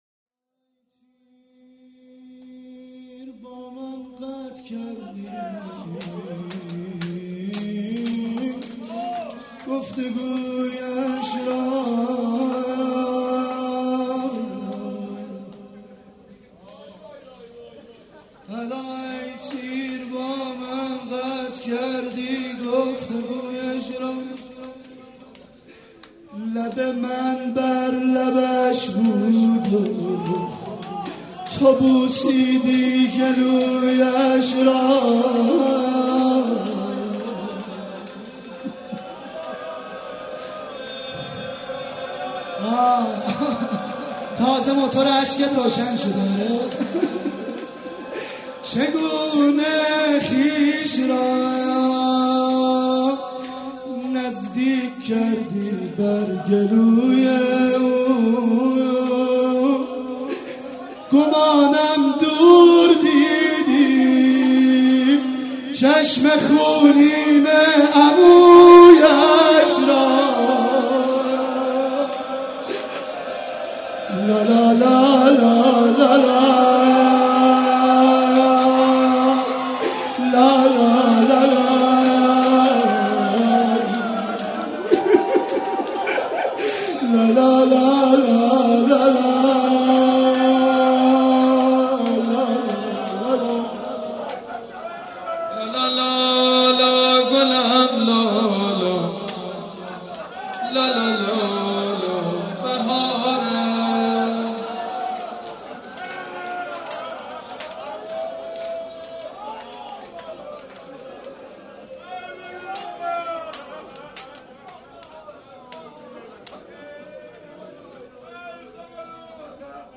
شب هفتم محرم95/هیئت ریحانه الحسین(س)